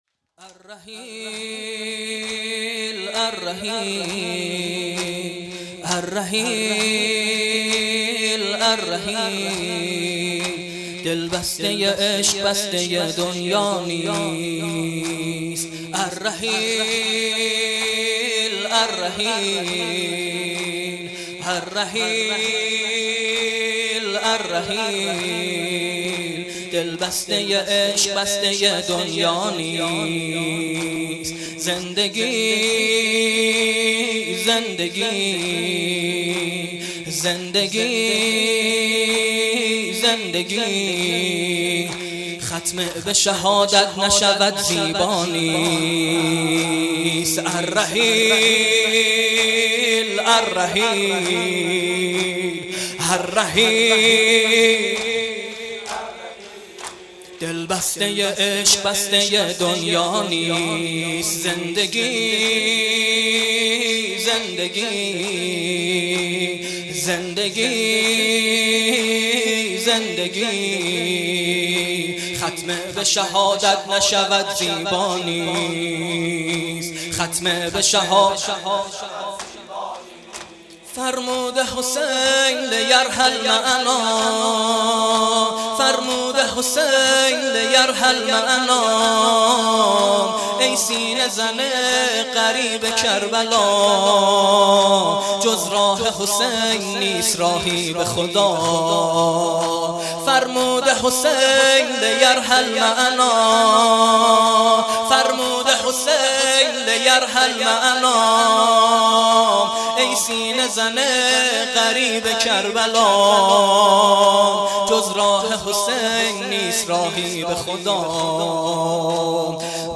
تک دم پایانی